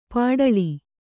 ♪ pāḍaḷi